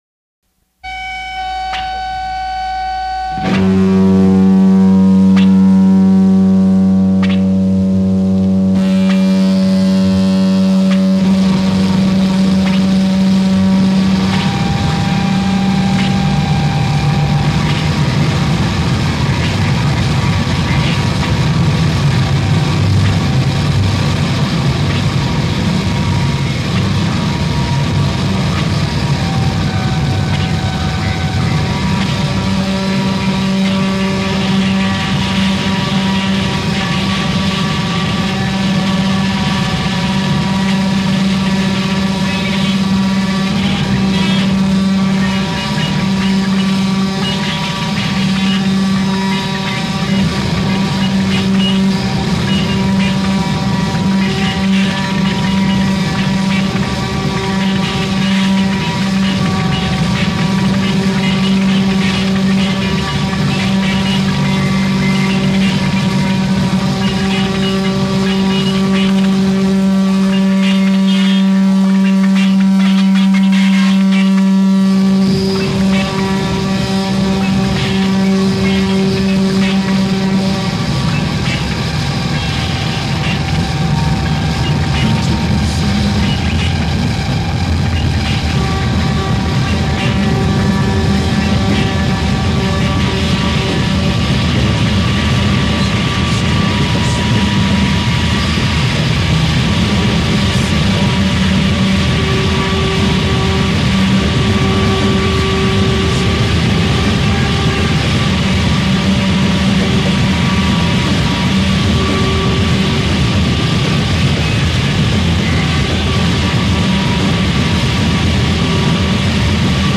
Super-soaked speed dirge.